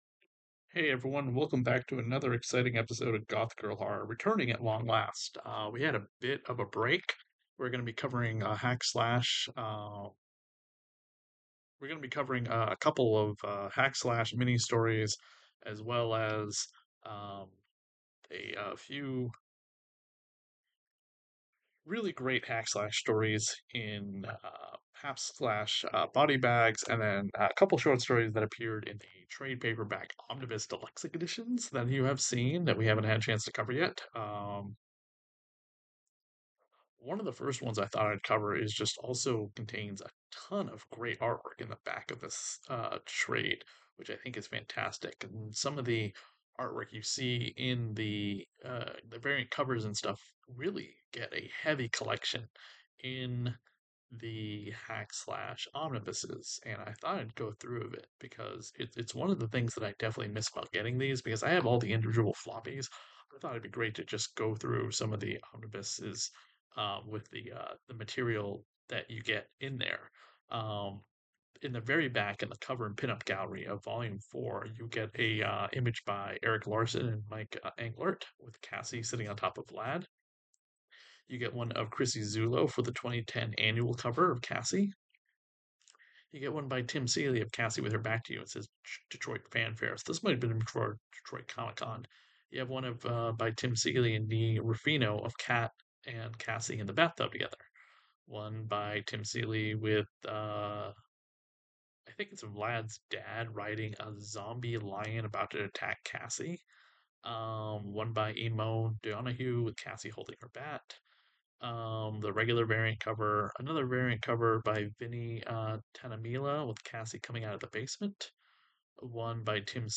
Short Story Review from the 4th Omnibus of Hack/Slash by Tim Seeley and Image Comics.